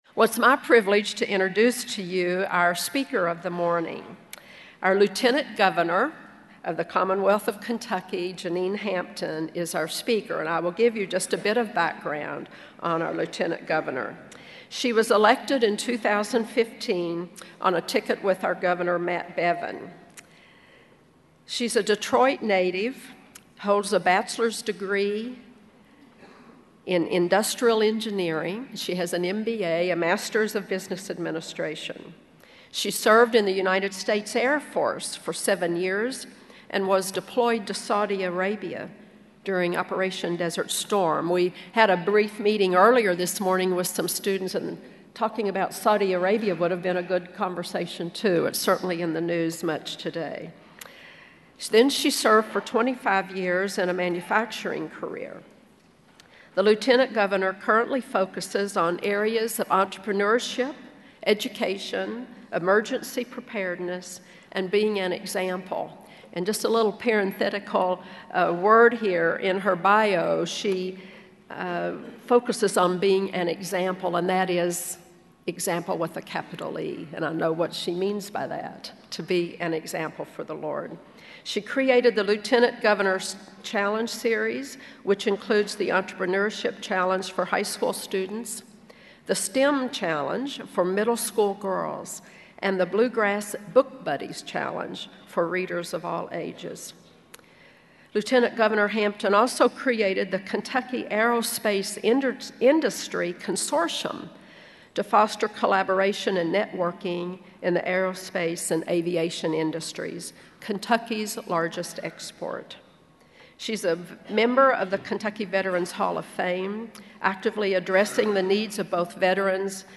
Lieutenant Governor Hampton speaks about how we must have faith and step out in order to pursue our God-given dreams.